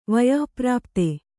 ♪ vayah prāpta